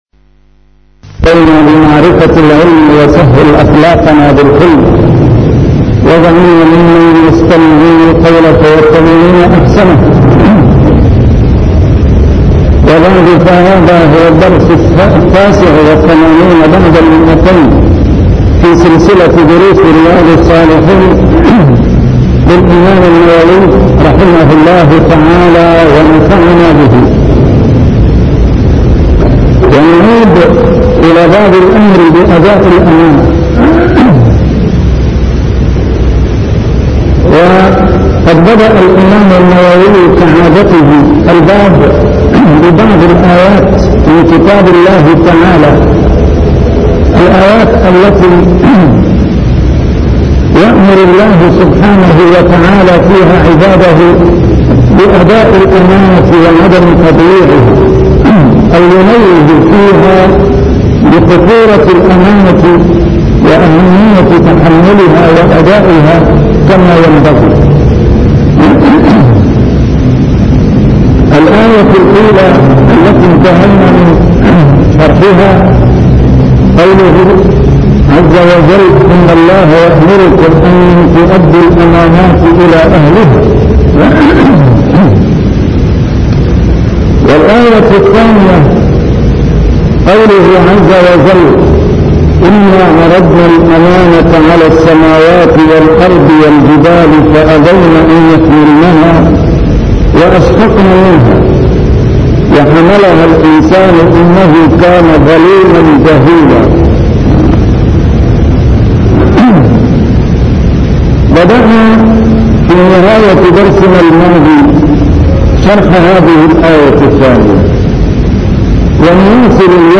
A MARTYR SCHOLAR: IMAM MUHAMMAD SAEED RAMADAN AL-BOUTI - الدروس العلمية - شرح كتاب رياض الصالحين - 289- شرح رياض الصالحين: الأمر بأداء الأمانة